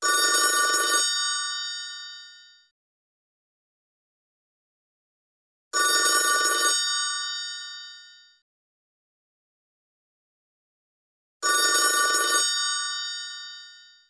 old-telefon_24943.mp3